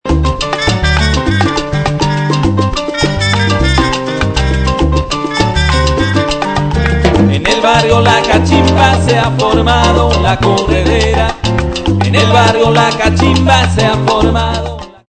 salsa